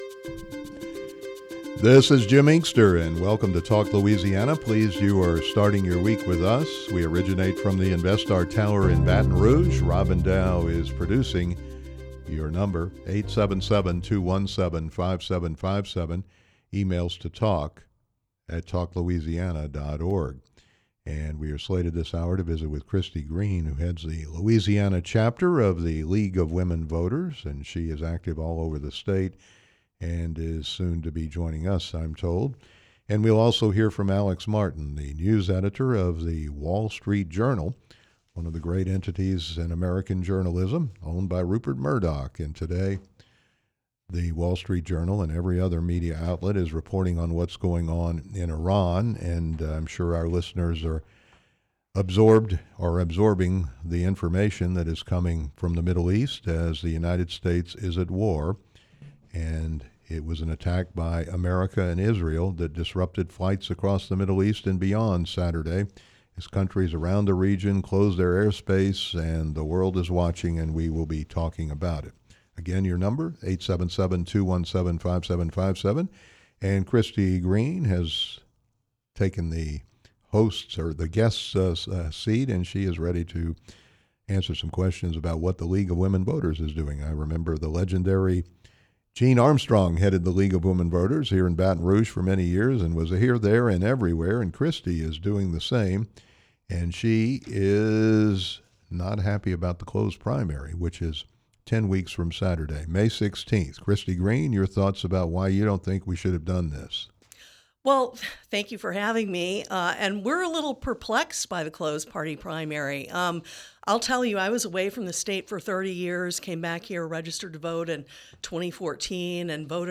radio program "Talk Louisiana